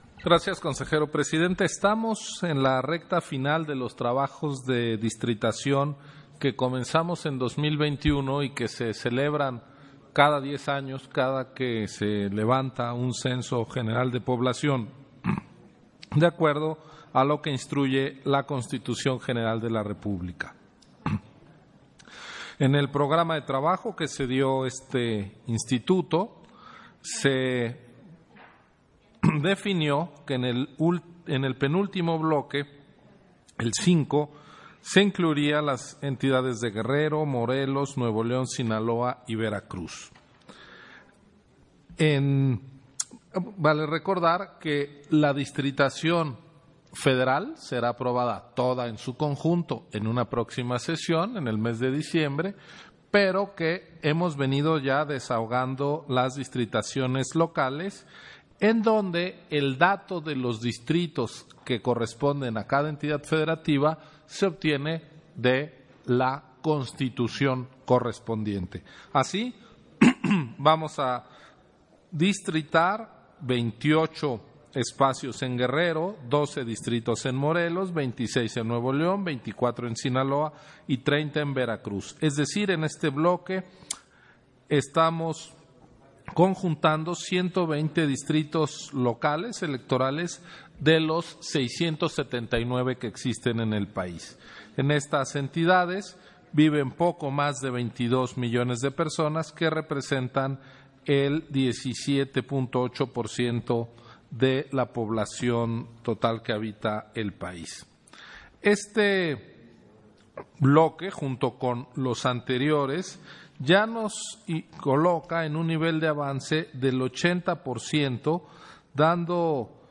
291122_AUDIO_INTERVENCIÓN-CONSEJERO-MURAYAMA_PUNTO-19-SESIÓN-ORD. - Central Electoral